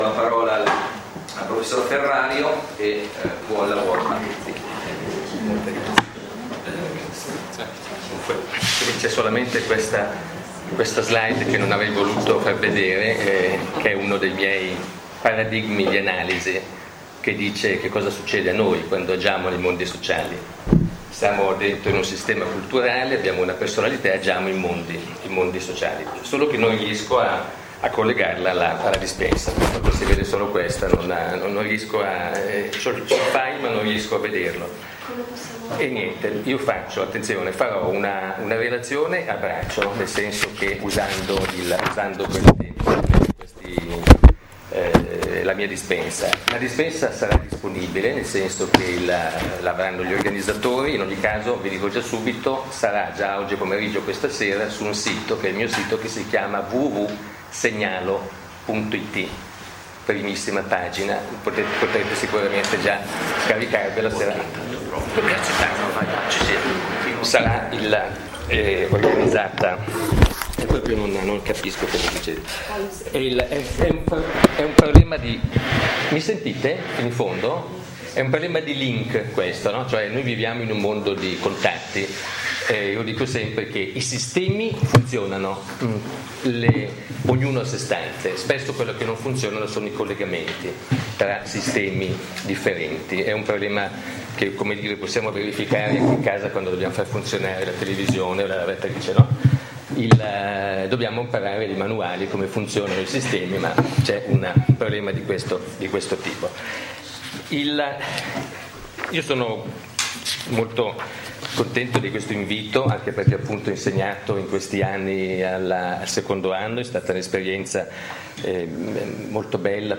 LE PROFESSIONI NEI SISTEMI DEI SERVIZI, relazione alla Università di Milano Bicocca / CareerDay Corso di Laurea Magistrale in Scienze Pedagogiche